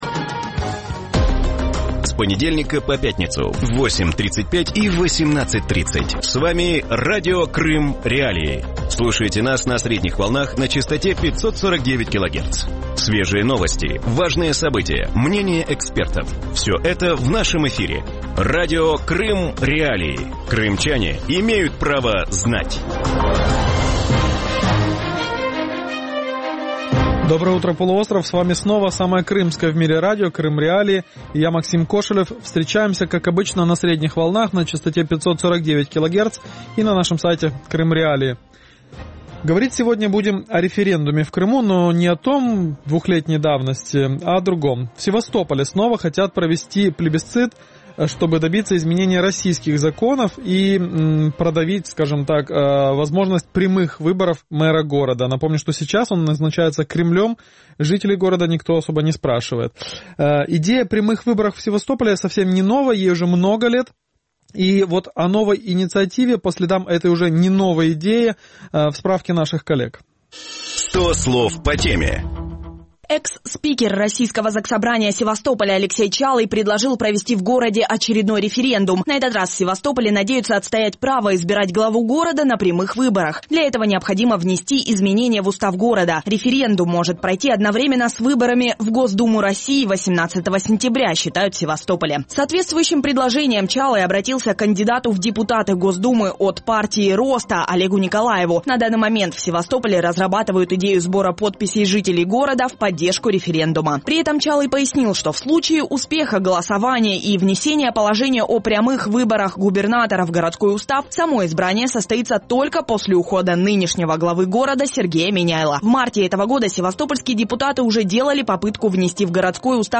В утреннем эфире Радио Крым.Реалии обсуждают насколько реальным может стать желание севастопольцев самостоятельно избирать мера города. По задумке инициаторов процесса, прямого голосования можно добиться через референдум.
Севастопольцы высказывают свое мнение в прямом эфире Радио Крым.Реалии.